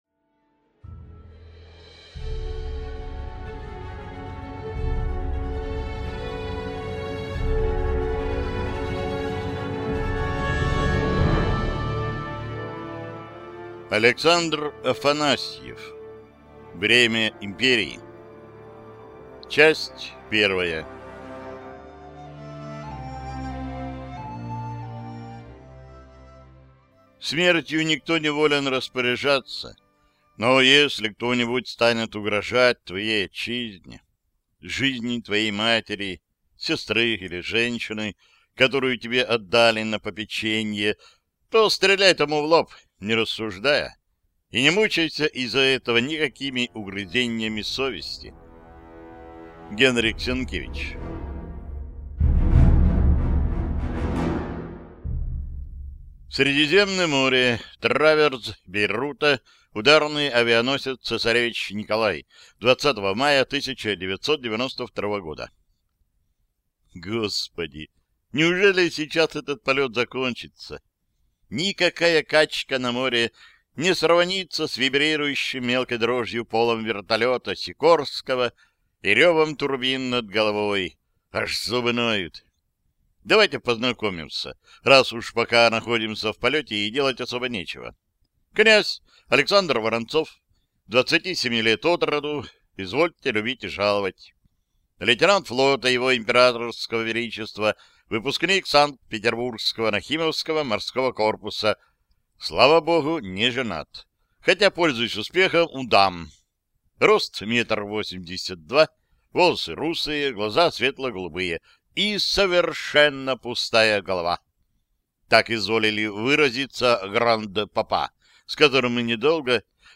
Аудиокнига Бремя империи (часть 1) | Библиотека аудиокниг